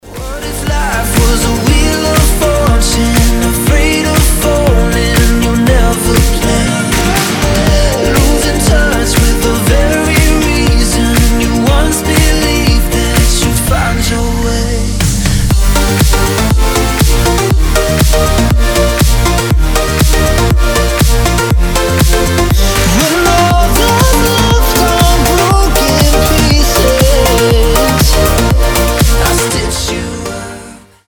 • Качество: 320, Stereo
мужской вокал
громкие
мелодичные
EDM
progressive house